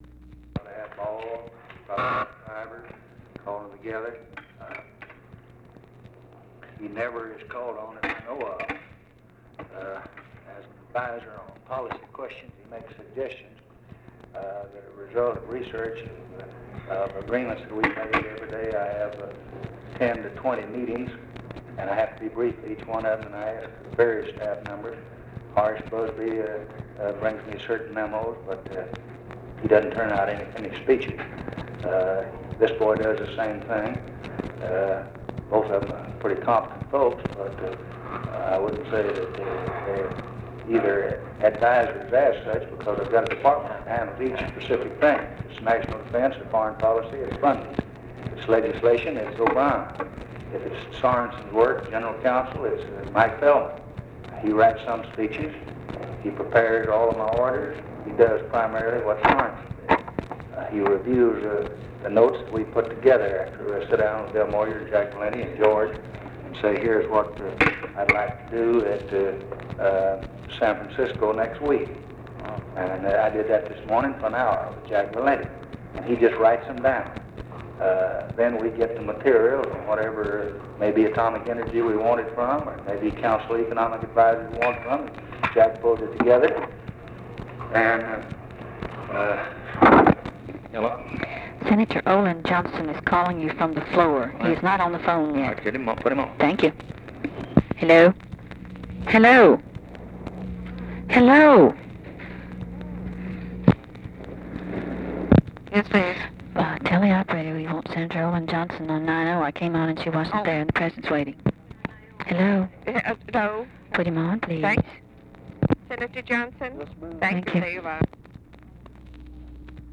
Conversation with OLIN JOHNSTON and OFFICE CONVERSATION, June 11, 1964
Secret White House Tapes